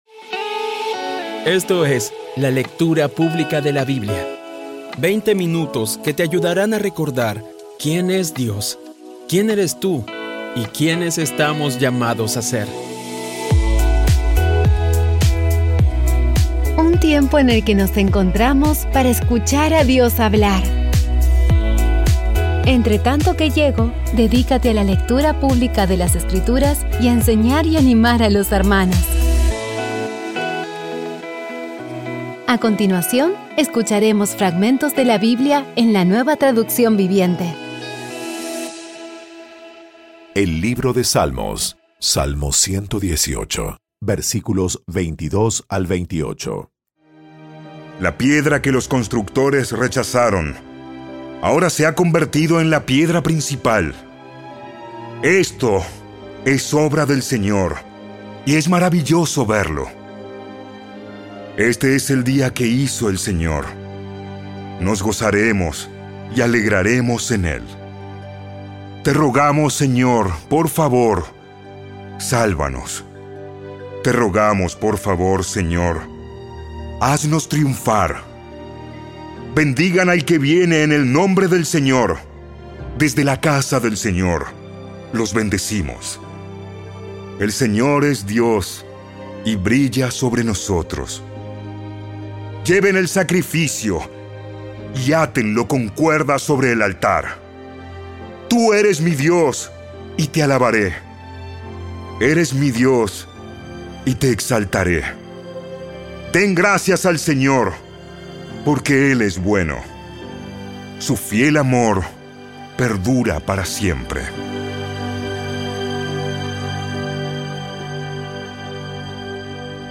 Audio Biblia Dramatizada Episodio 295
Poco a poco y con las maravillosas voces actuadas de los protagonistas vas degustando las palabras de esa guía que Dios nos dio.